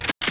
Amiga 8-bit Sampled Voice
1 channel
reload.mp3